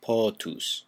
Ääntäminen
France (Ouest): IPA: [bwaːʁ]